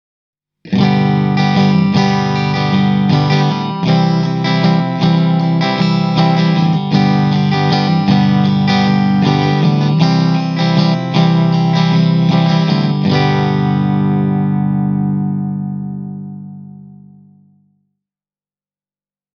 TOOB 12J soi selvästi ilmaavammin, kun taas TOOB 12R:ssä on hieman ryhdikkäämpi luonne ja enemmän potkua bassorekisterissä.
PUHDAS STRATO
the-toob-r-e28093-clean-strat.mp3